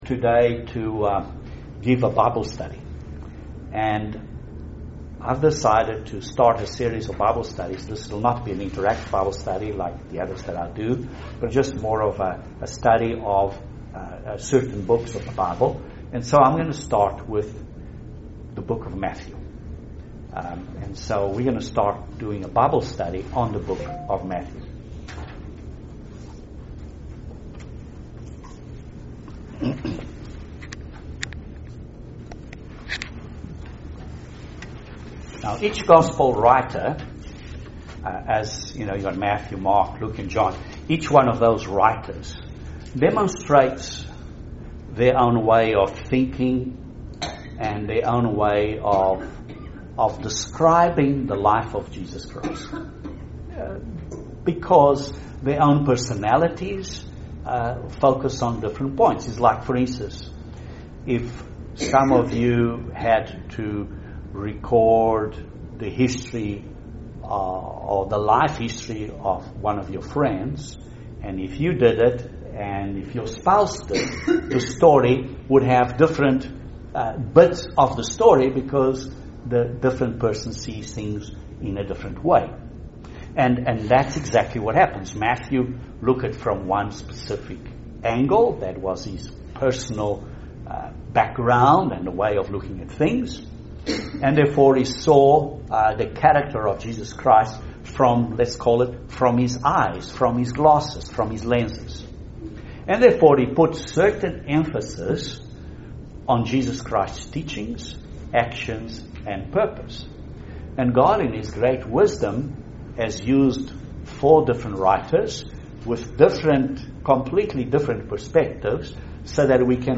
An excellent Bible study through the book of Matthew from a Jewish perspective.